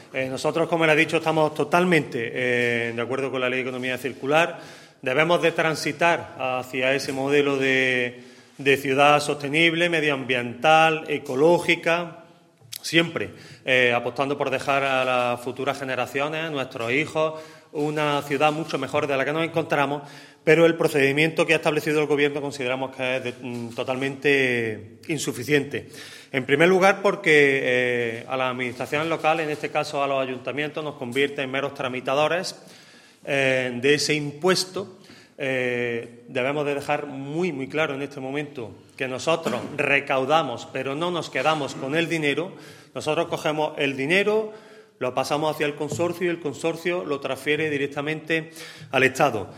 El alcalde de Antequera, Manolo Barón, el teniente de alcalde delegado de Hacienda y Aguas del Torcal, Antonio García Acedo, y el concejal de Medio Ambiente, José Manuel Fernández, han comparecido en rueda de prensa para denunciar públicamente la utilización que esta llevando a cabo el Gobierno de España sobre los ayuntamientos a la hora de obligarlos a actuar como “meros recaudadores de impuestos” en relación a la creación del nuevo Impuesto sobre el Depósito de Residuos en Vertederos, la Incineración y la Coincineración (IDRV).
Cortes de voz